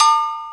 Perc (13).wav